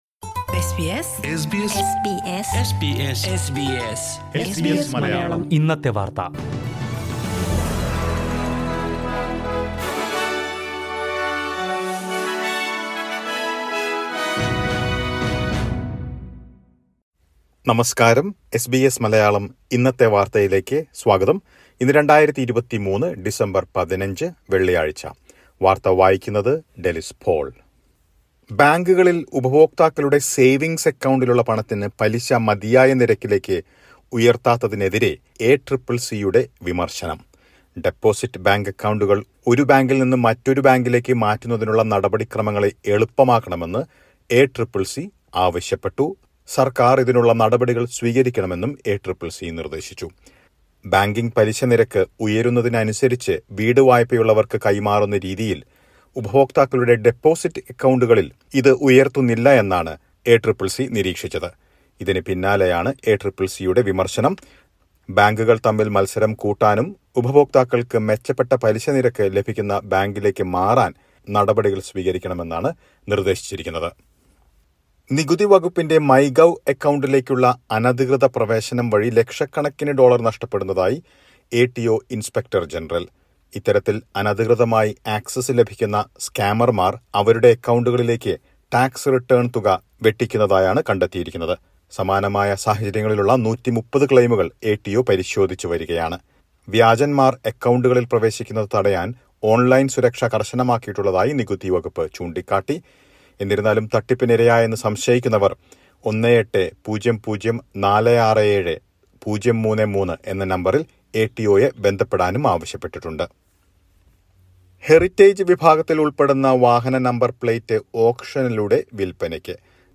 2023 ഡിസംബര്‍ 15ലെ ഓസ്‌ട്രേലിയയിലെ ഏറ്റവും പ്രധാന വാര്‍ത്തകള്‍ കേള്‍ക്കാം.